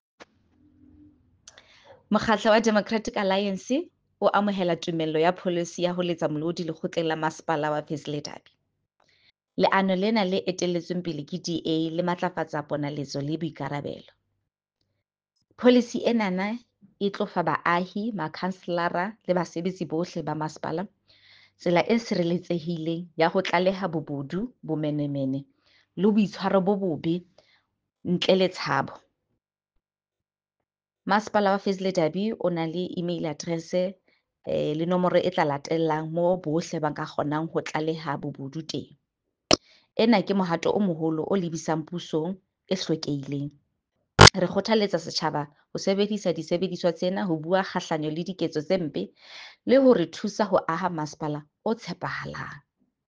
Sesotho soundbites by Cllr Mbali Mnaba and